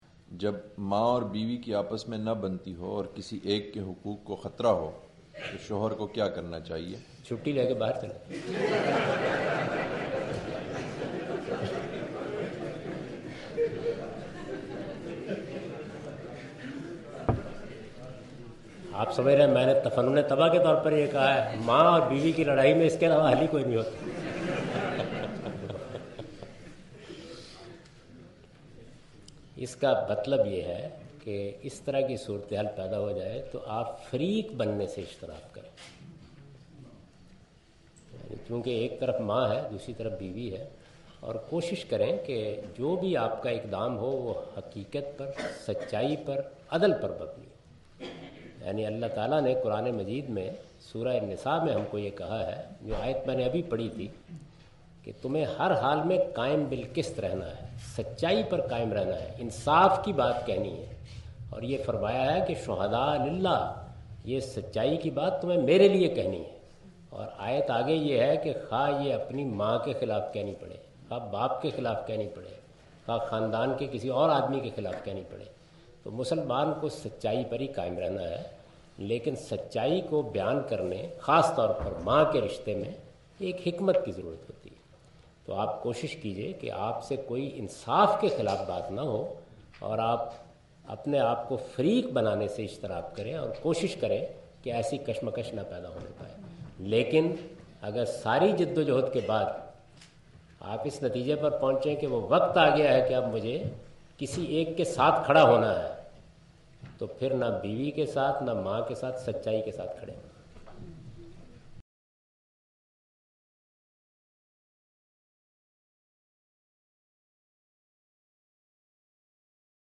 Javed Ahmad Ghamidi answer the question about "conflict between mother and wife" during his visit to Queen Mary University of London UK in March 13, 2016.
جاوید احمد صاحب غامدی اپنے دورہ برطانیہ 2016 کےدوران کوئین میری یونیورسٹی اف لندن میں "والدہ اور بیوی میں اختلاف" سے متعلق ایک سوال کا جواب دے رہے ہیں۔